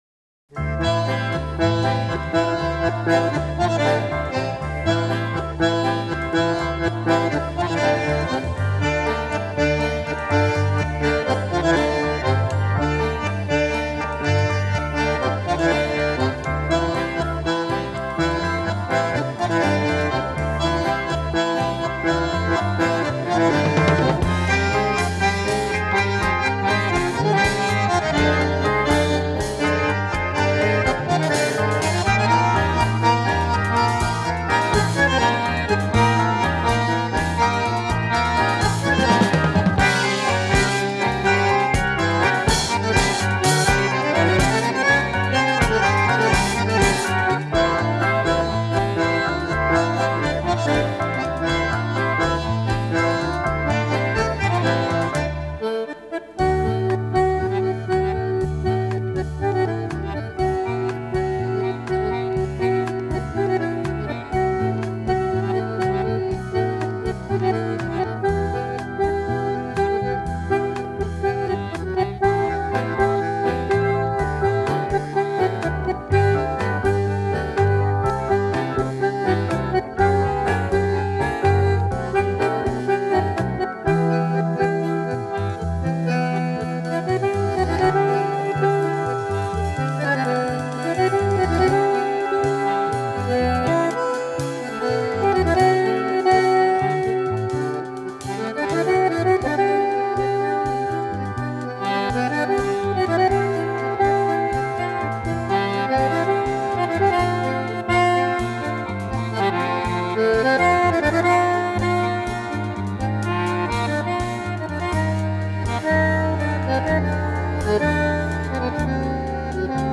Танго
bandoneon
Recorded in Milan, Italyin May 1974